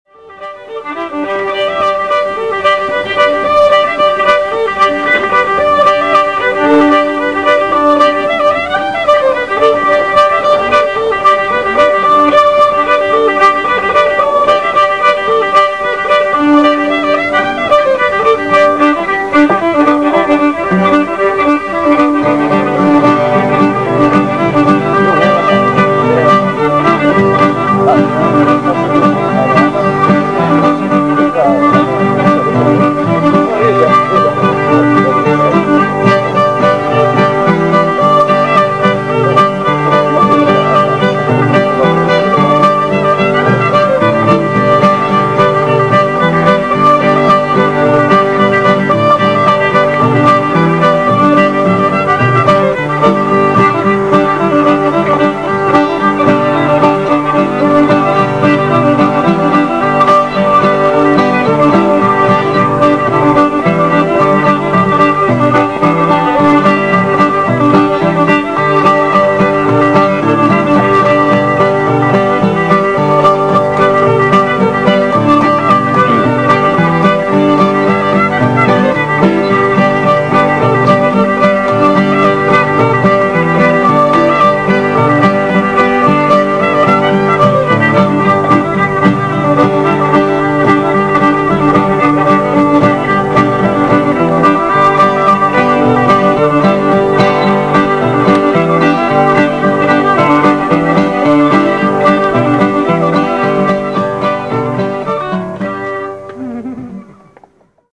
** Old-Time JAM **
* 2004 TAKARAZUKA BLUEGRASS FEST.